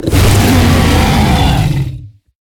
Sfx_creature_snowstalker_roar_land_01.ogg